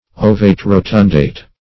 Search Result for " ovate-rotundate" : The Collaborative International Dictionary of English v.0.48: Ovate-rotundate \O"vate-ro*tund"ate\, a. Having a form intermediate between that of an egg and a sphere; roundly ovate.
ovate-rotundate.mp3